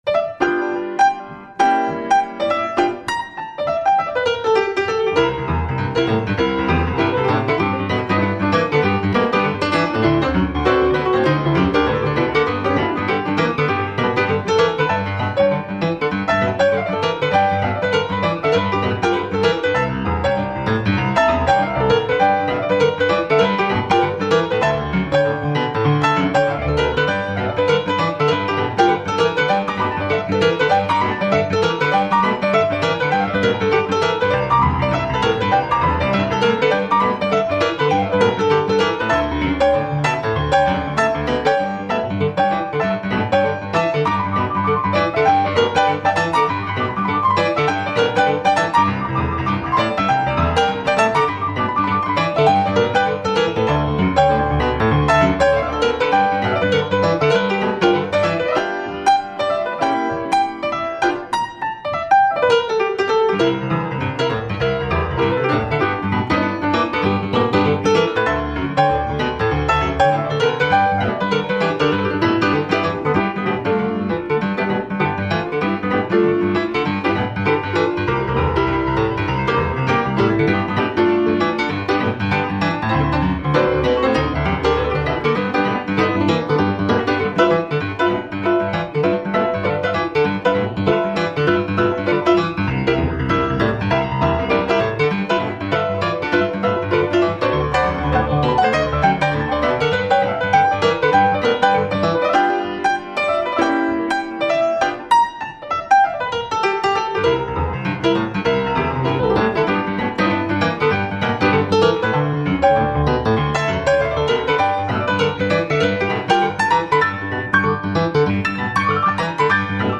We play boogie woogie, blues, stride and a balad
• We play on two Steinway & Sons grand pianos